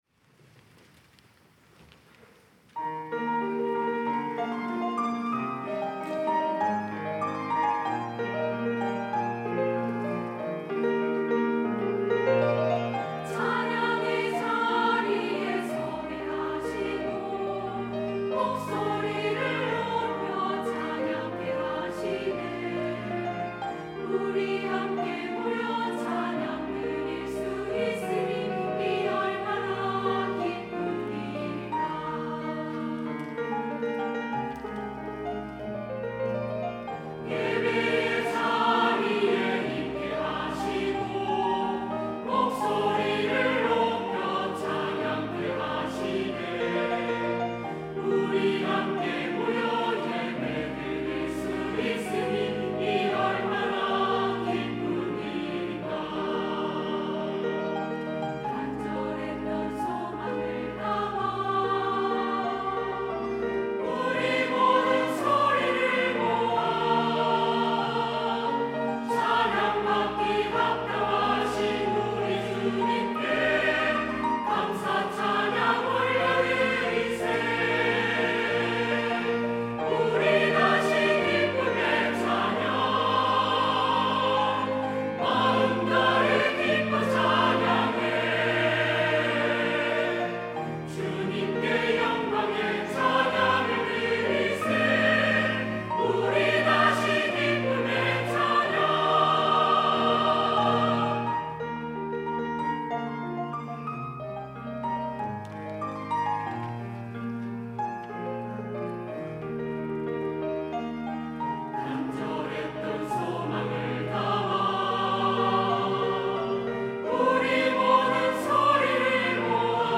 할렐루야(주일2부) - 우리 다시 기쁨의 찬양
찬양대